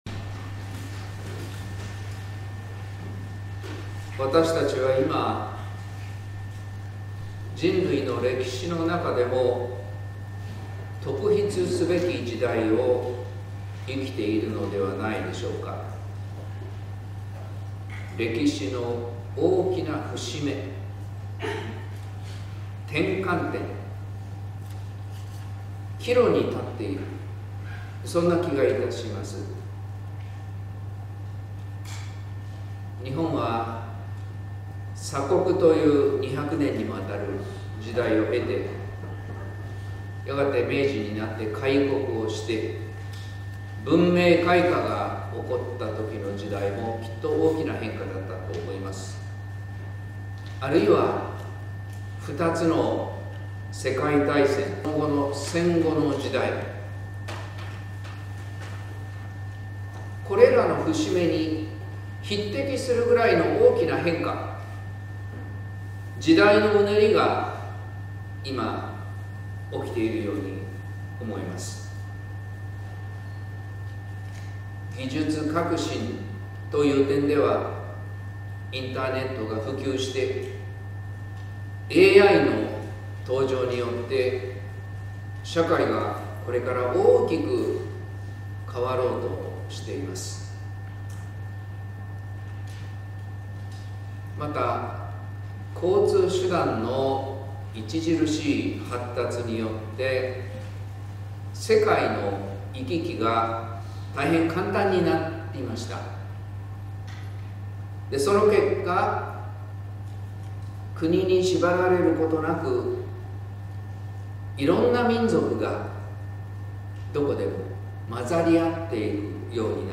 説教「羊に学ぶ心」